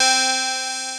DOBLEAD C5-R.wav